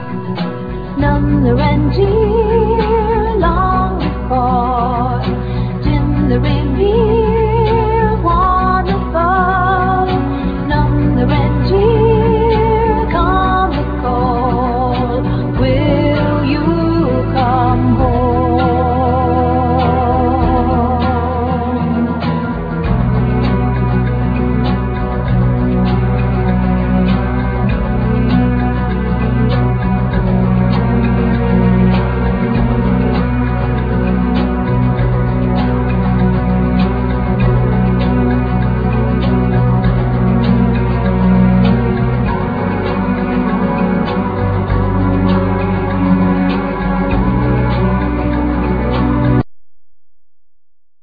Vocals,Mandolin,Ac.guitar
Piano
Flute
Cello
String Quartet
Barabuka,Drums,Percussions,Classical guitar
Keyboards,Sound effects
Tiple,Charango,Glissando,Angel Harp